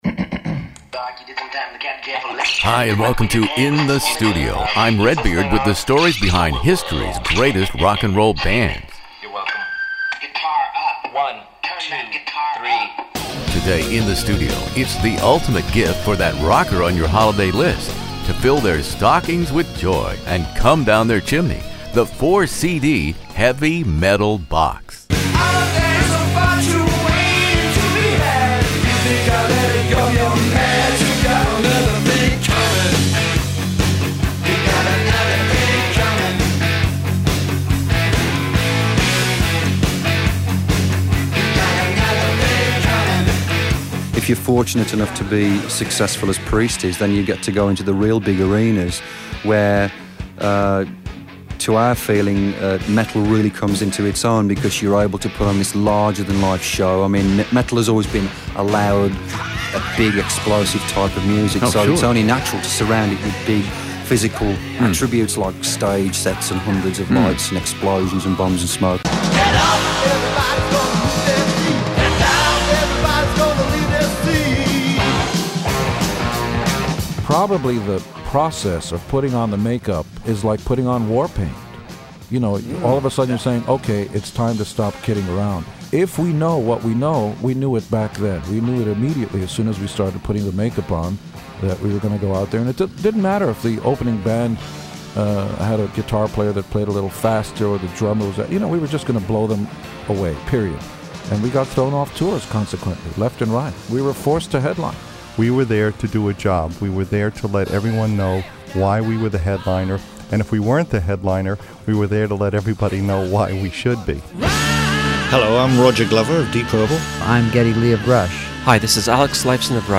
My guests are the leaders of the pioneering bands who forged the foundation of Heavy Metal: Deep Purple’s Ian Gillan and Roger Glover; Alice Cooper; Paul Stanley and Gene Simmons of KISS; Rob Halford of Judas Priest; the late Ronnie Montrose & his discovery Sammy Hagar; Alex Lifeson and Geddy Lee of Rush.